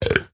burp.ogg